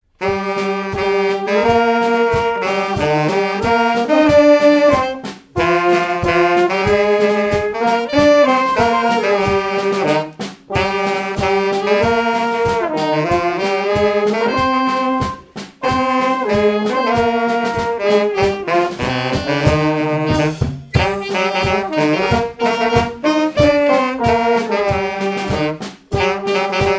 Répertoire pour un bal et marches nuptiales
Pièce musicale inédite